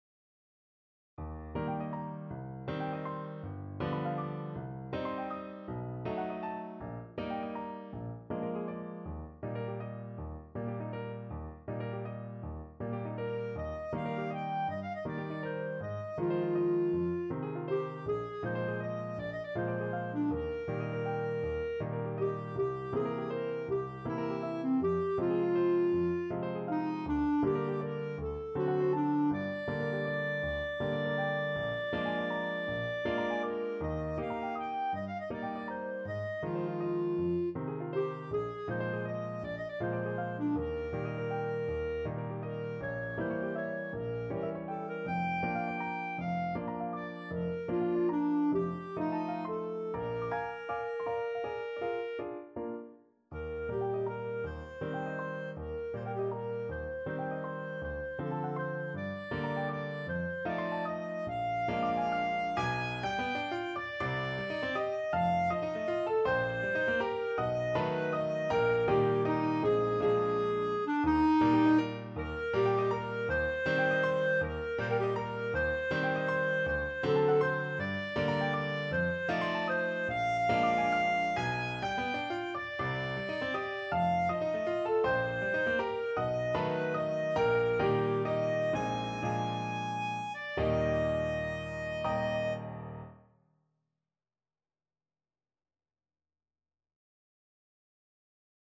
C5-Ab6
6/8 (View more 6/8 Music)
Classical (View more Classical Clarinet Music)